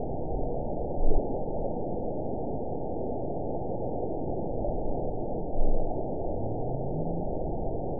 event 911303 date 02/22/22 time 01:42:53 GMT (3 years, 9 months ago) score 9.60 location TSS-AB01 detected by nrw target species NRW annotations +NRW Spectrogram: Frequency (kHz) vs. Time (s) audio not available .wav